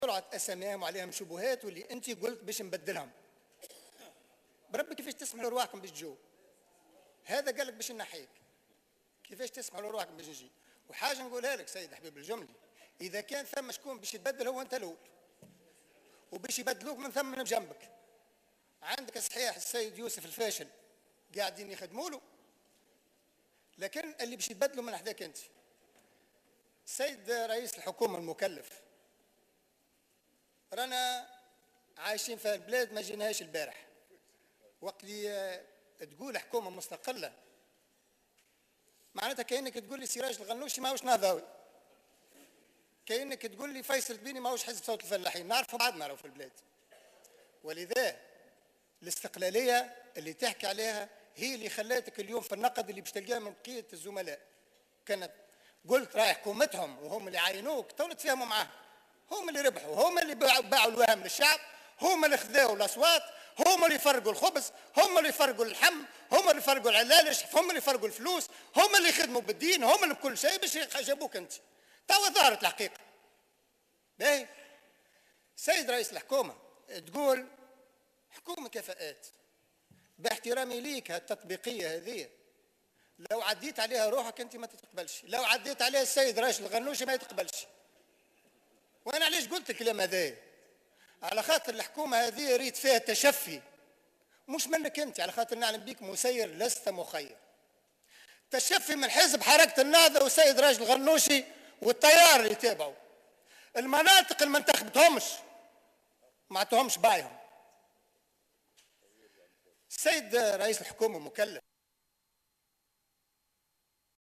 انتقد النائب عن حزب صوت الفلاحين فيصل التبيني لرئيس الحكومة المكلف الحبيب الجملي خلال جلسة منح الثقة للحكومة المقترحة اليوم الجمعة التطبيقة التي اعتمدها لإختيار أعضاء حكومته.